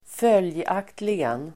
Uttal: [²f'öl:jak:tligen]